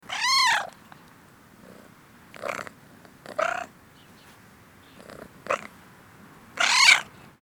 جلوه های صوتی
دانلود صدای گربه درحال خوابیدن و خور خور کردن گربه از ساعد نیوز با لینک مستقیم و کیفیت بالا